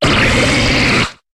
Cri de Muplodocus dans Pokémon HOME.